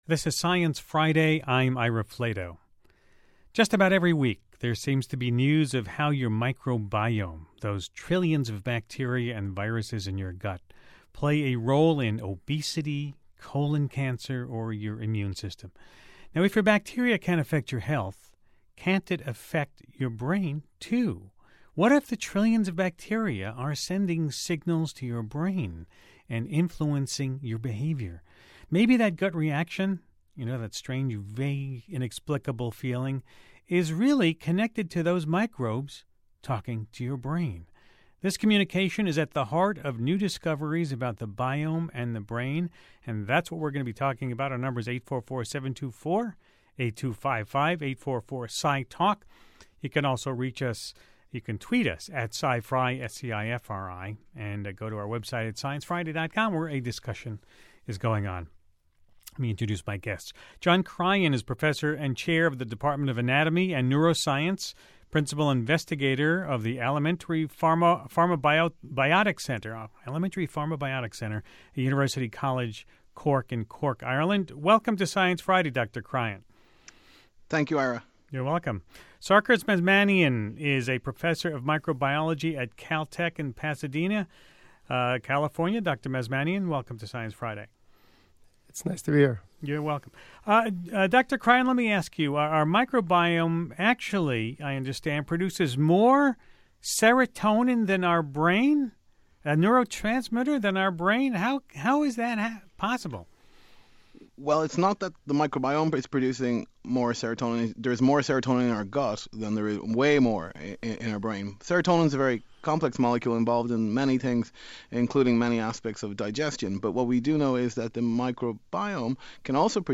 Researchers discuss how the microbiome might play a role in anxiety, depression, and autism.